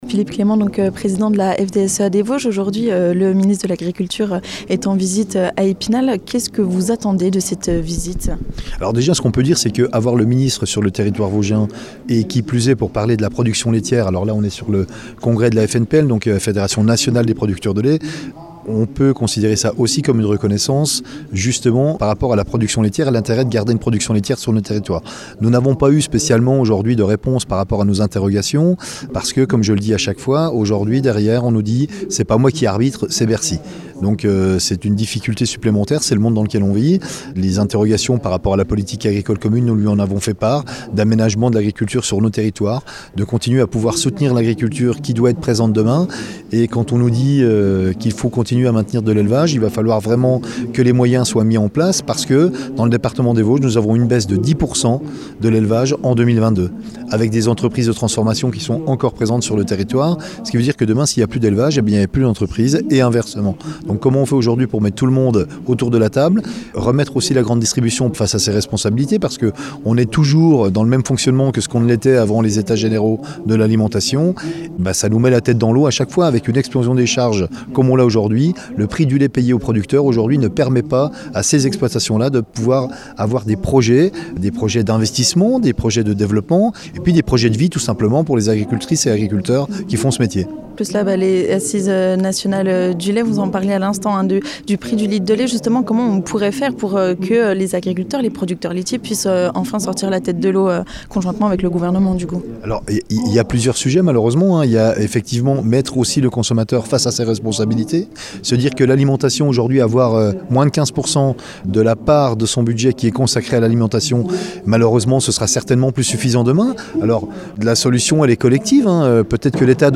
Ce jeudi, le Ministre de l'Agriculture Marc Fesneau était en visite dans les Vosges à l'occasion des Assises Nationales du Lait.